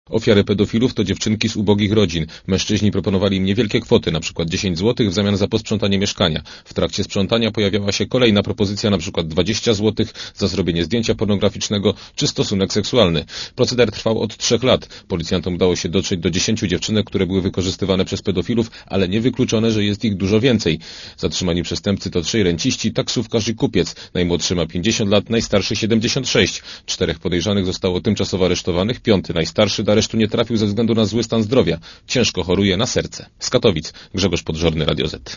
reportera Radia ZET*Komentarz audio